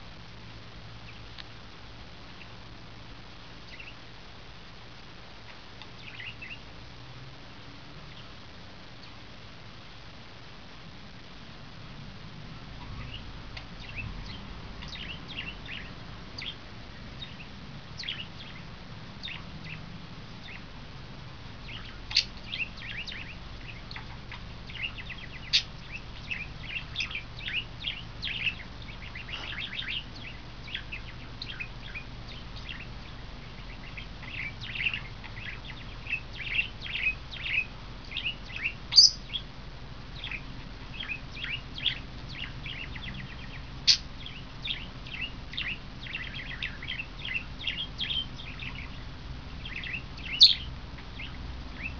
テーブルの下からこっそり録音しました。
(あ、音声ファイルですよ。インコの声の)